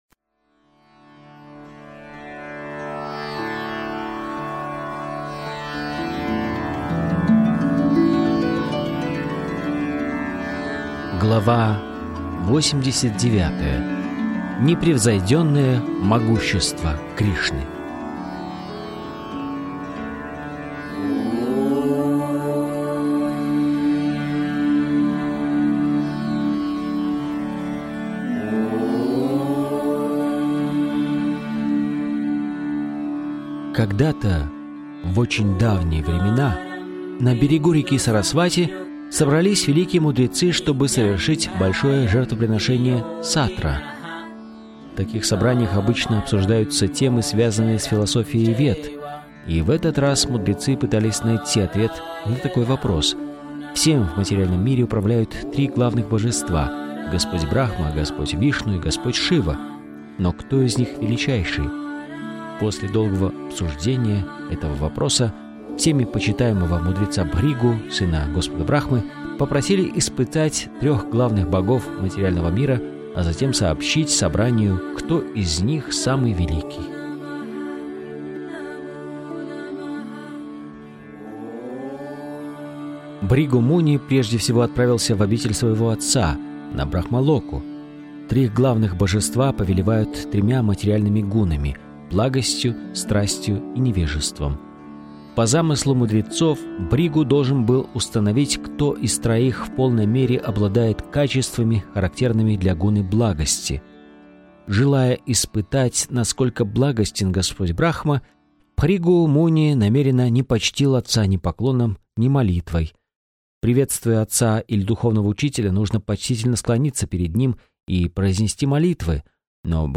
Автор Абхай Чаран Де Бхактиведанта Свами Прабхупада из аудиокниги "Кришна.